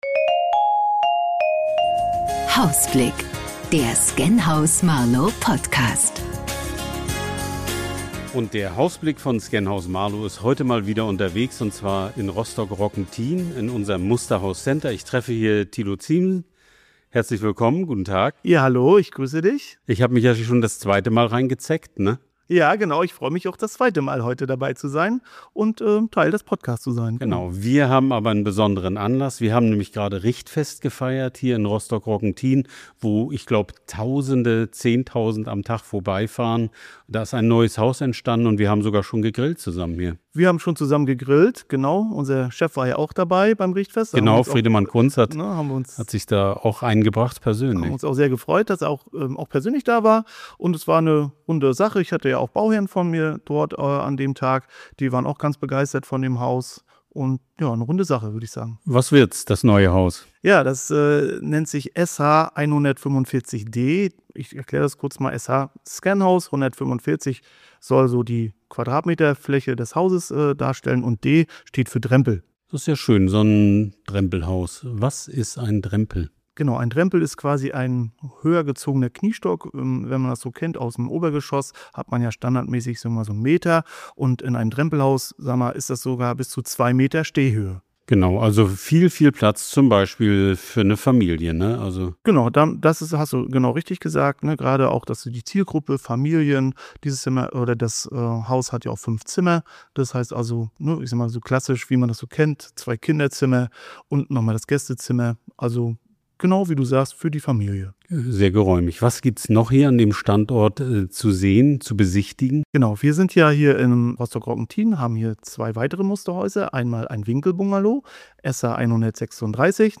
Der Hausblick war heute in Rostock-Roggentin, wo eines unserer neuen Musterhäuser entsteht.